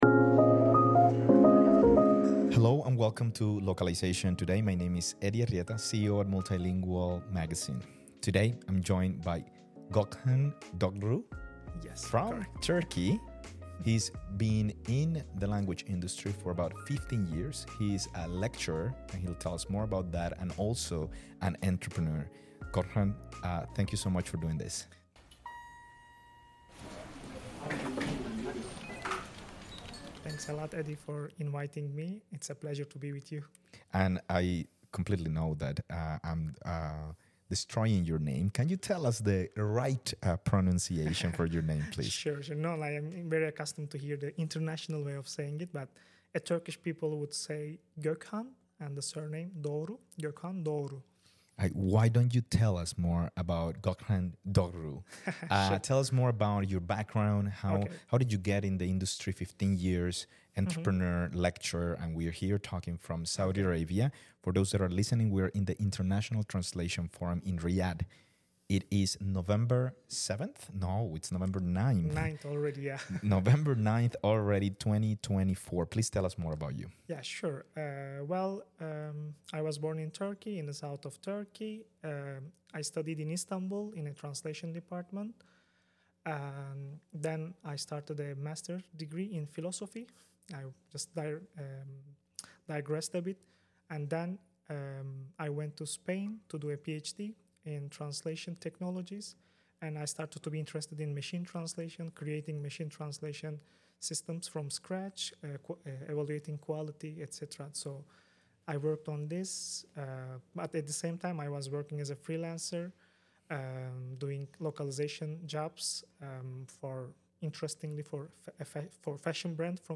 Recorded live at the International Translation Forum in Riyadh